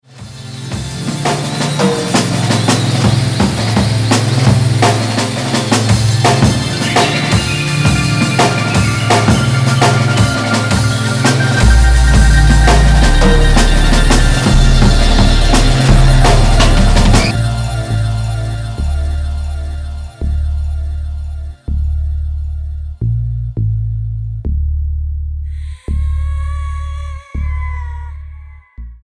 STYLE: Hip-Hop